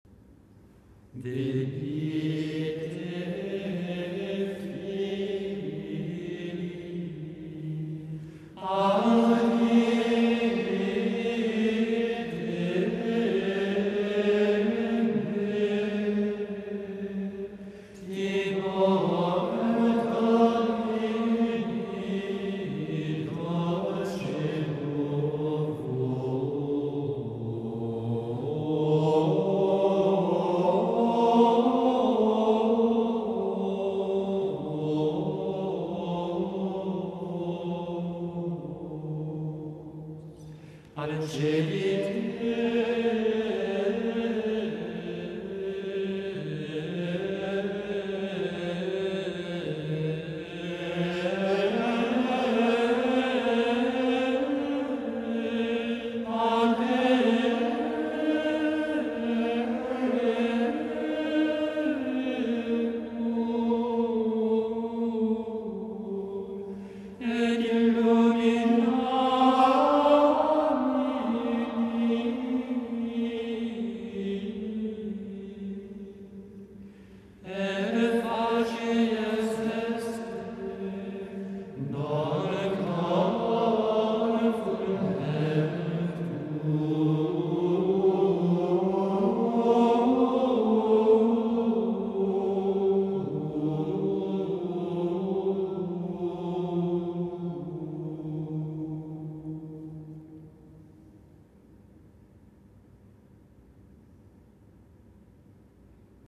C’est encore une mélodie du 5ème mode qui vient habiller ce beau graduel au message joyeux et clair.
L’intonation est douce, aimante.
En contraste, la dernière phrase apparaît plus sobre.
À mesure qu’on approche de la fin, on élargit le mouvement.
un moine de Triors